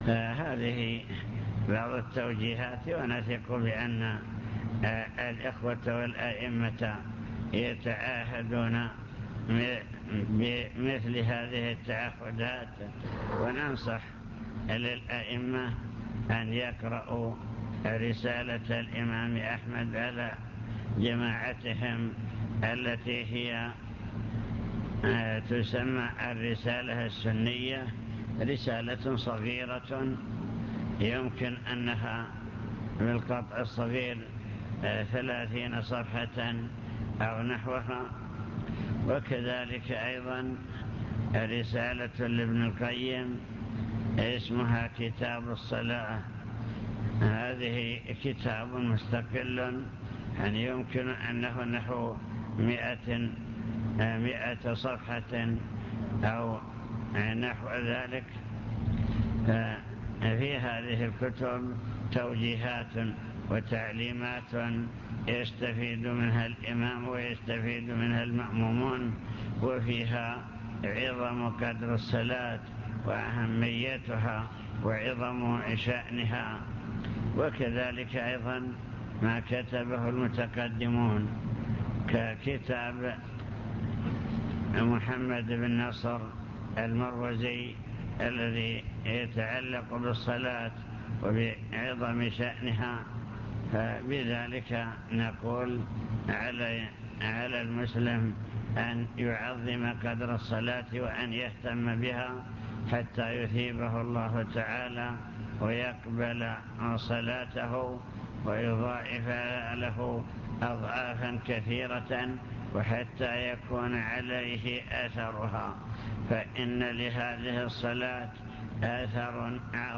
المكتبة الصوتية  تسجيلات - محاضرات ودروس  محاضرة في بدر بعنوان: وصايا عامة